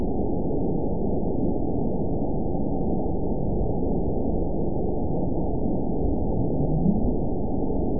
event 922868 date 04/29/25 time 13:31:03 GMT (7 months ago) score 9.32 location TSS-AB02 detected by nrw target species NRW annotations +NRW Spectrogram: Frequency (kHz) vs. Time (s) audio not available .wav